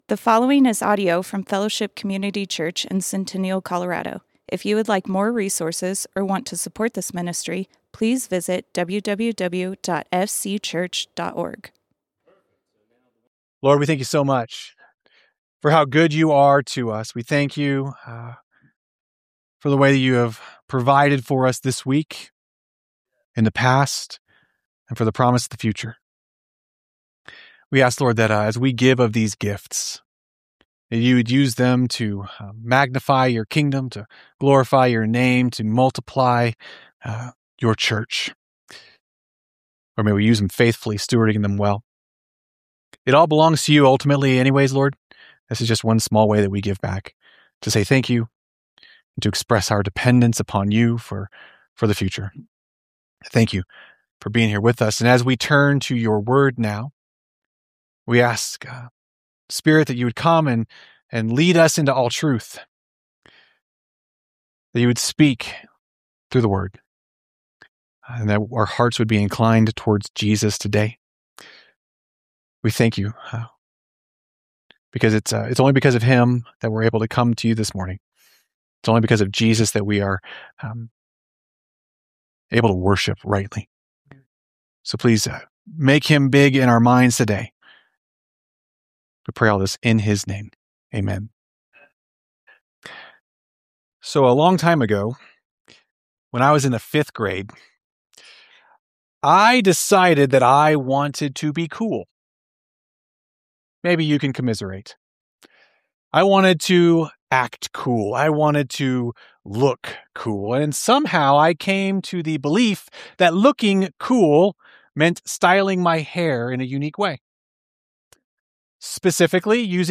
Fellowship Community Church - Sermons The Advocate We Need Play Episode Pause Episode Mute/Unmute Episode Rewind 10 Seconds 1x Fast Forward 30 seconds 00:00 / 40:49 Subscribe Share RSS Feed Share Link Embed